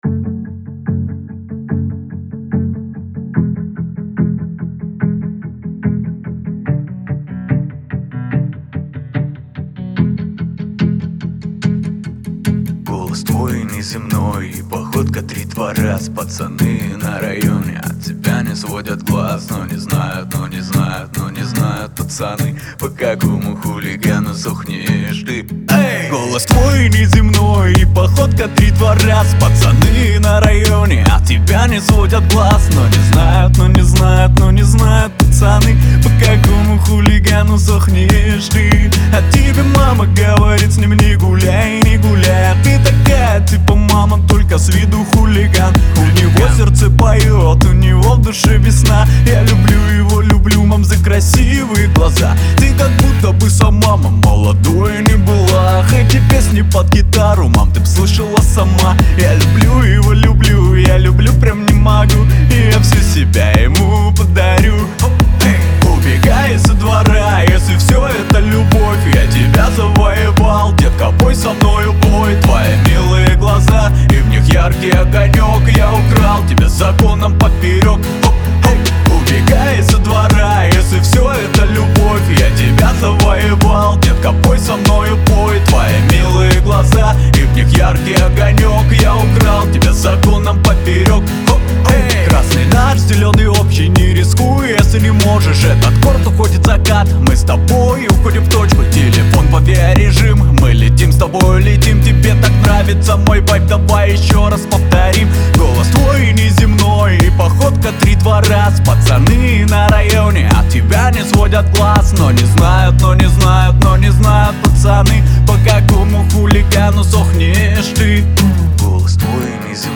мелодичный вокал с ритмичными битами